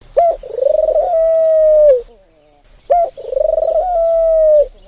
Tourterelle turque albinos
Streptopelia decaocto
tourterelle_t.mp3